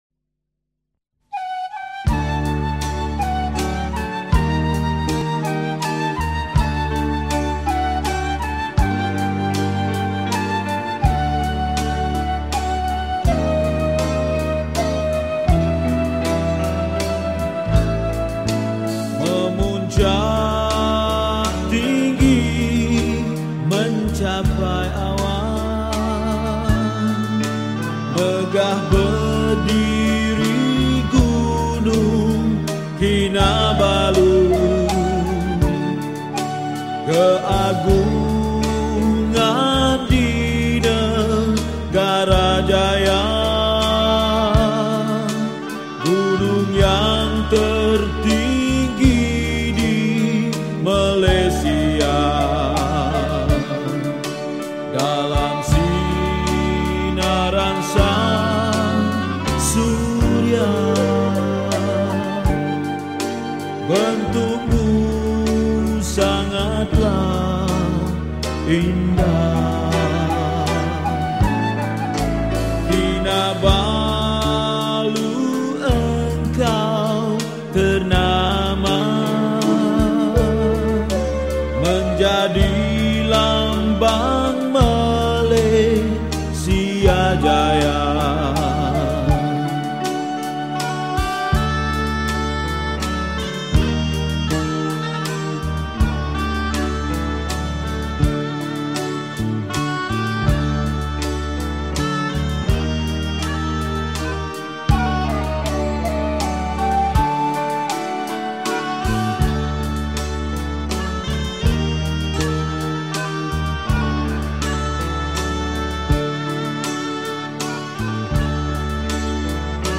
Malay Songs , Patriotic Songs